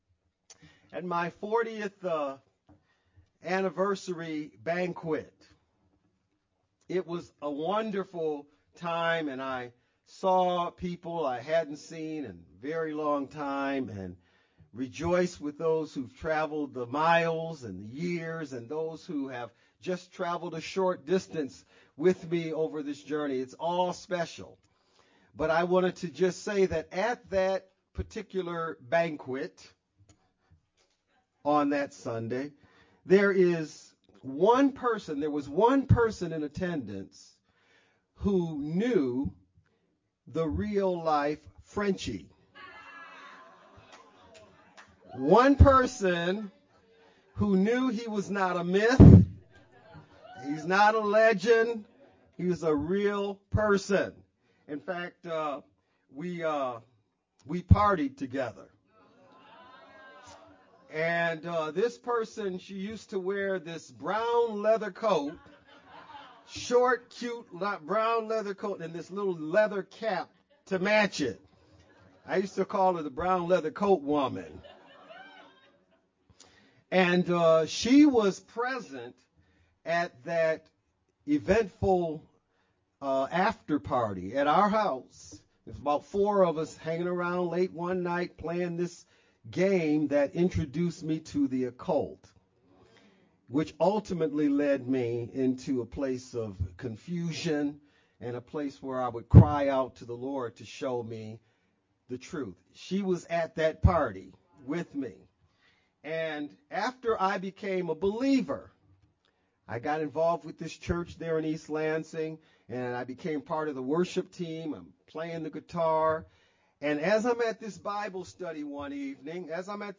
(Testimony)
Guest Speaker